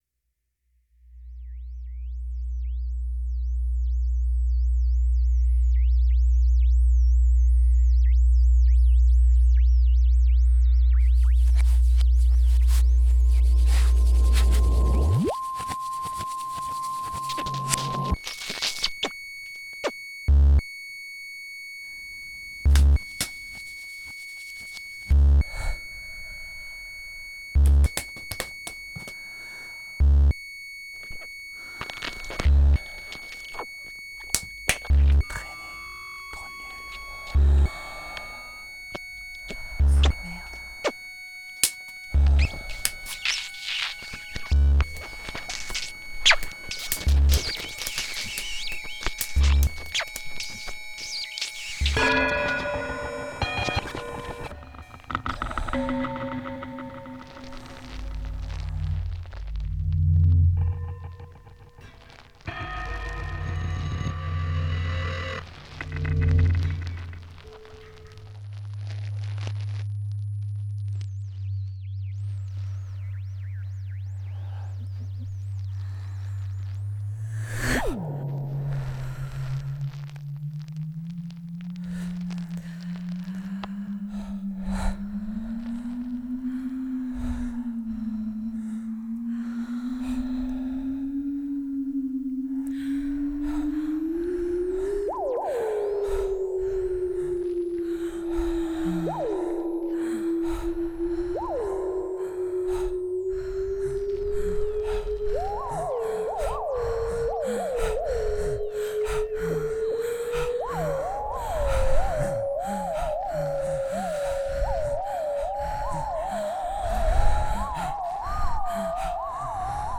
groupe de musique électroaocustique et expérimentale